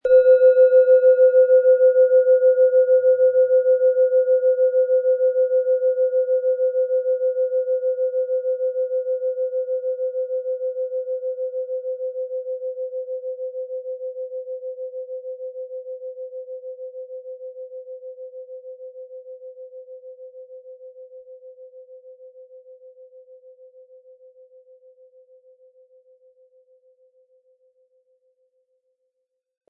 Tibetische Bauch-Herz- und Kopf-Klangschale, Ø 12,4 cm, 400-500 Gramm, mit Klöppel
Im Sound-Player - Jetzt reinhören hören Sie den Original-Ton dieser Schale. Wir haben versucht den Ton so authentisch wie machbar hörbar zu machen, damit Sie hören können, wie die Klangschale bei Ihnen klingen wird.
HerstellungIn Handarbeit getrieben
MaterialBronze